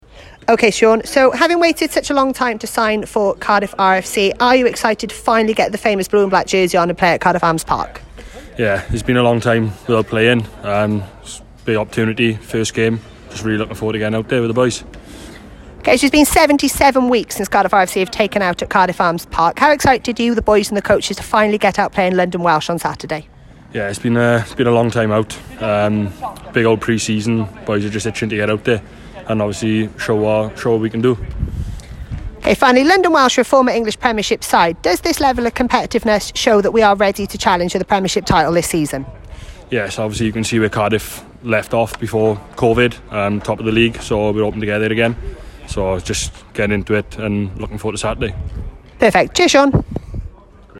Prematch interview